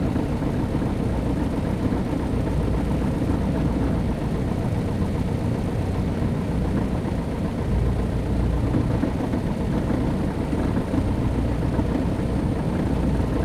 engine-idle.wav